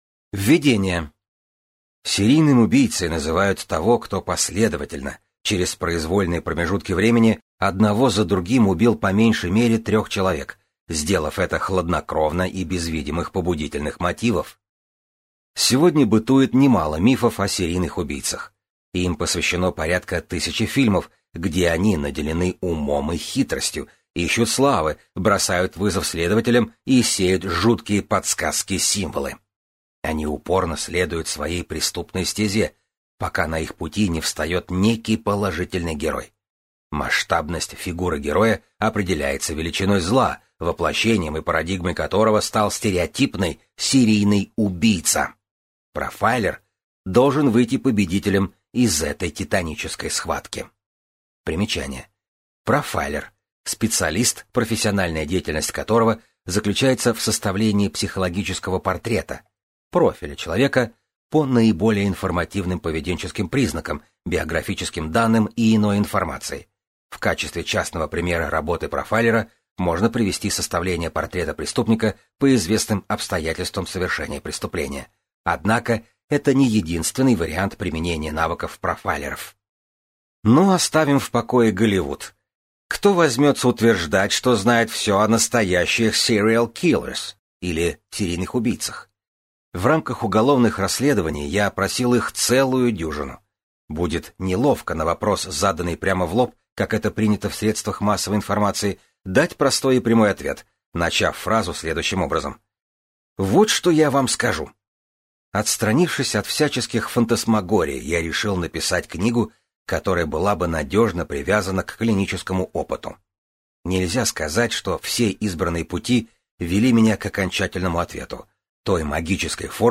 Аудиокнига Мозг серийного убийцы. Реальные истории судебного психиатра | Библиотека аудиокниг